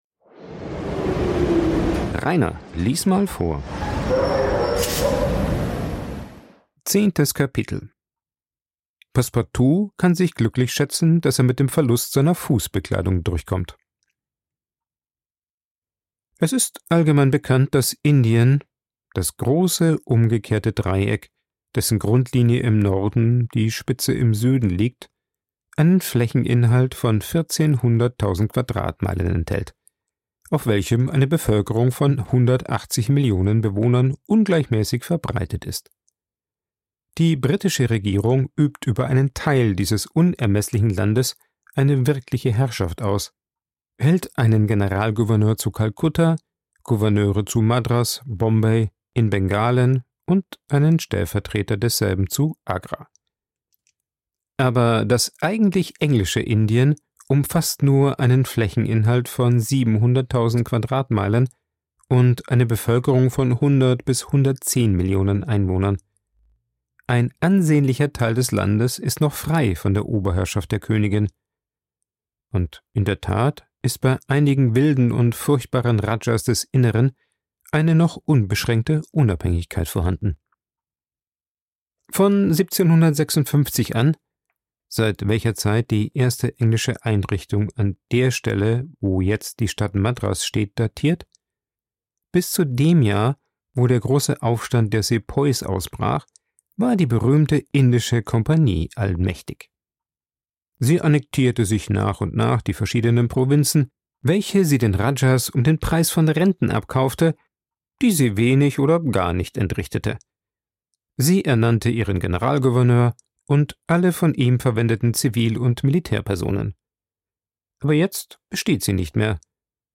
Passepartout bringt sich durch Unwissenheit in einer Pagode in arge Bedrängnis und schafft es gerade noch zum Bahnhof zu kommen, allerdings ohne Hut, Schuhe und Einkäufe. Vorgelesen
aufgenommen und bearbeitet im Coworking Space Rayaworx, Santanyí, Mallorca.